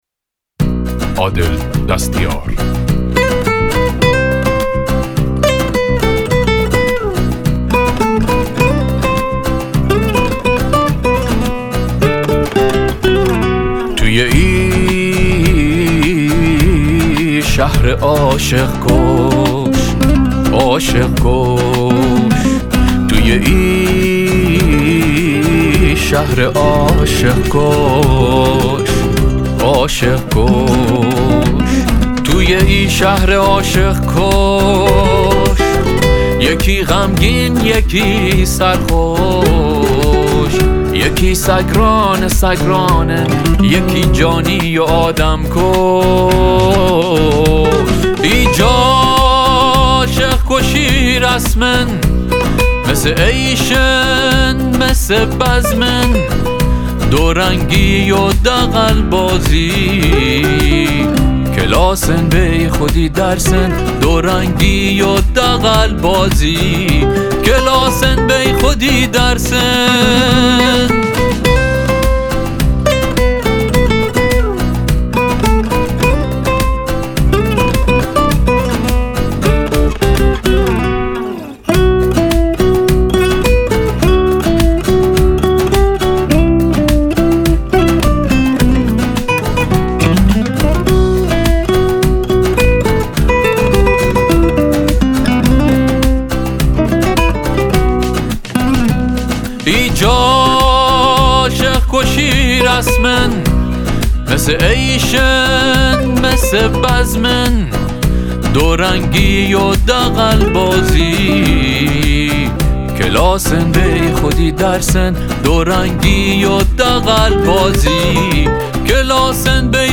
تهیه شده در استودیو برتر بندرعباس